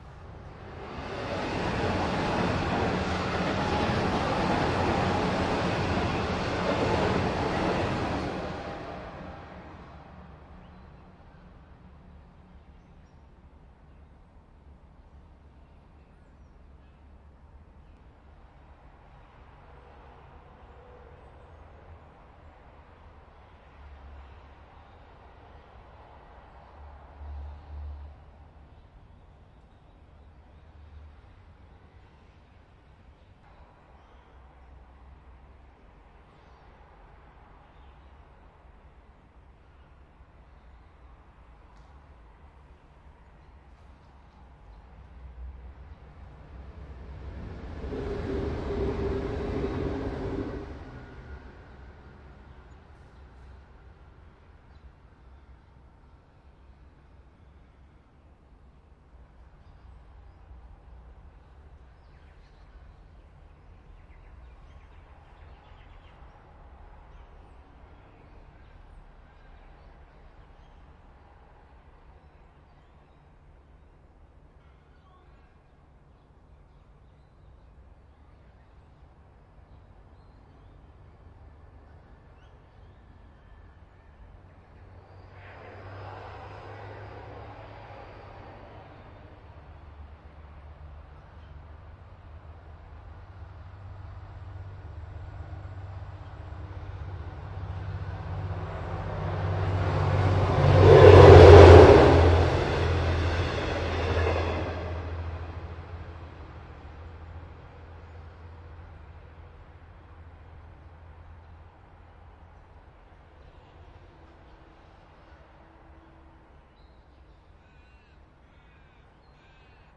人工列车1
描述：火车的样本听起来不太真实。通过计算机合成生成样本。
Tag: 交通运输 机车 地铁 火车 铁轨 旅游